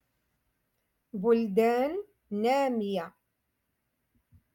Moroccan Dialect-Rotation Five- Lesson Forty Nine